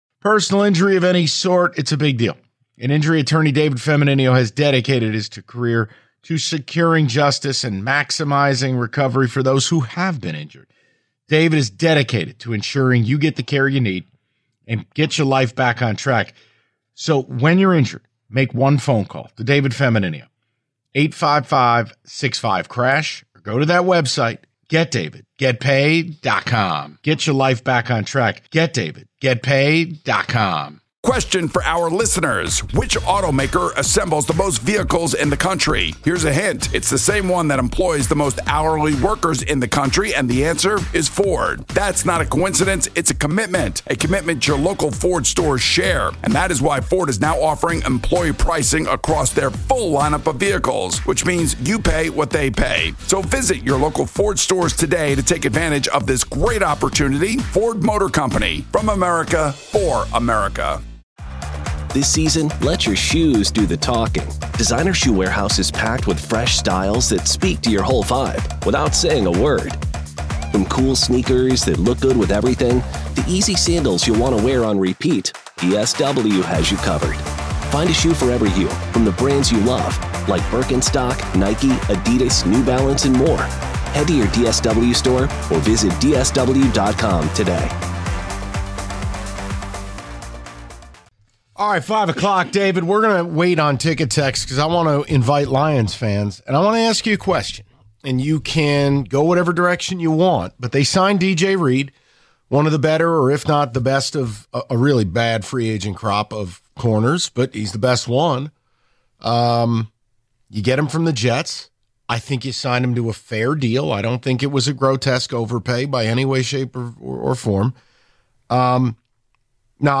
The guys wrap up the show in the final hour giving their final thoughts and taking more of your calls on the Lions signing D.J. Reed and other potential moves in the works for Brad Holmes.